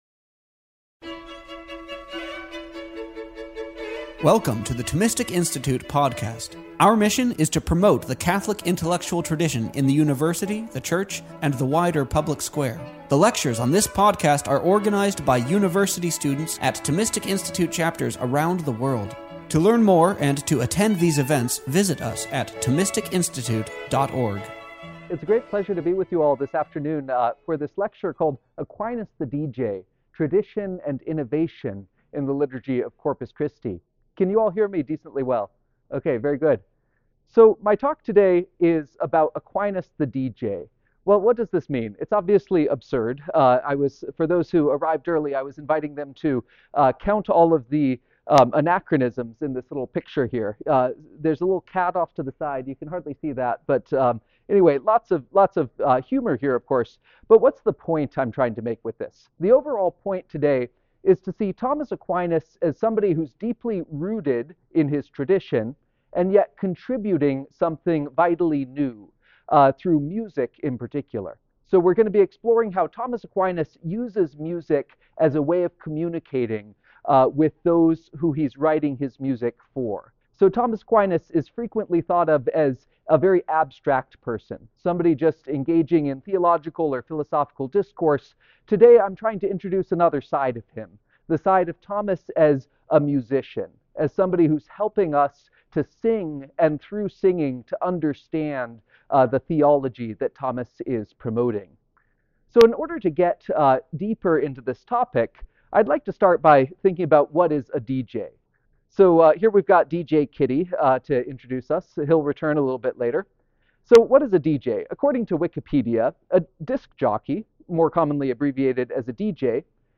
The Thomistic Institute Podcast features the lectures and talks from our conferences, campus chapters events, intellectual retreats, livestream events, and much more.